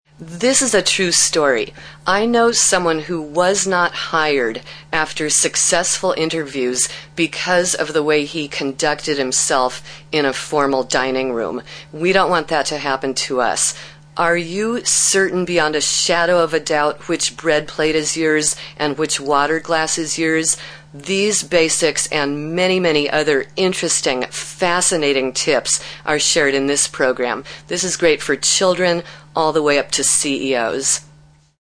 31 sec voice sample — also available in